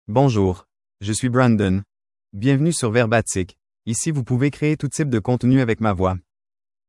Brandon — Male French (Canada) AI Voice | TTS, Voice Cloning & Video | Verbatik AI
MaleFrench (Canada)
Brandon is a male AI voice for French (Canada).
Voice sample
Brandon delivers clear pronunciation with authentic Canada French intonation, making your content sound professionally produced.